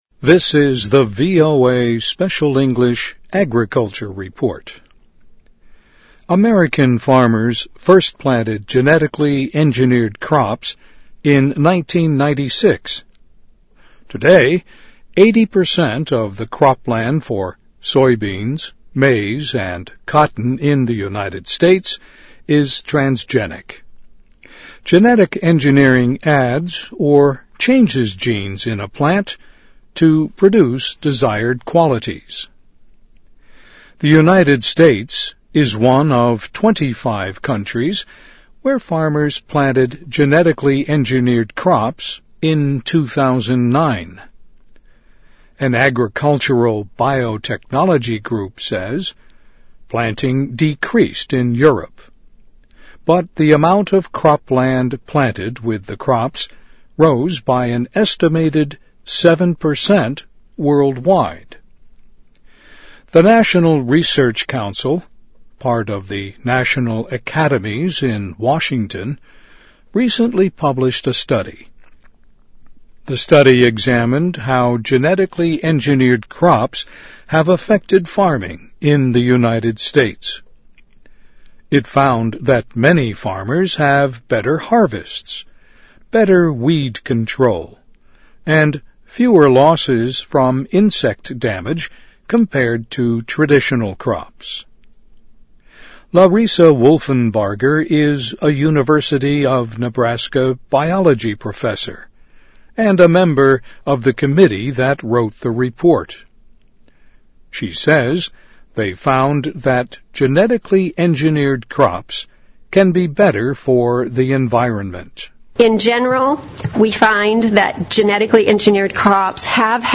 VOA慢速英语2010-Agriculture Report - Transgenic Crops Get 听力文件下载—在线英语听力室